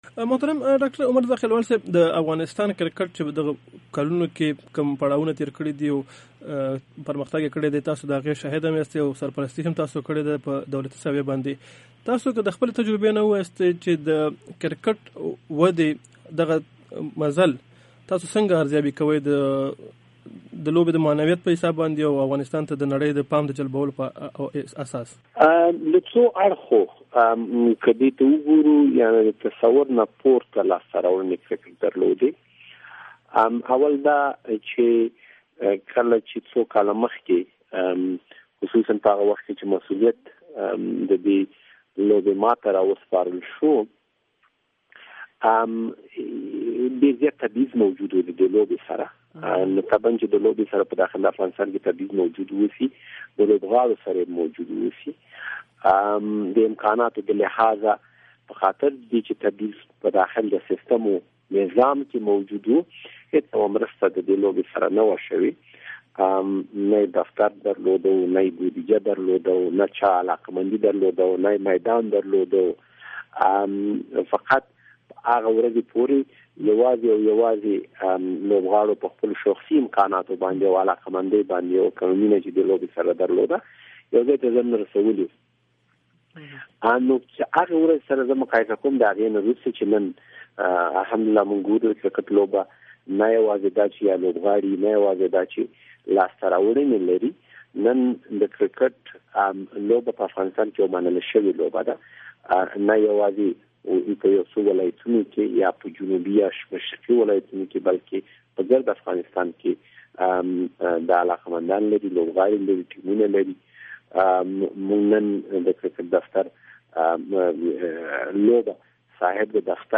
zakhilwal interview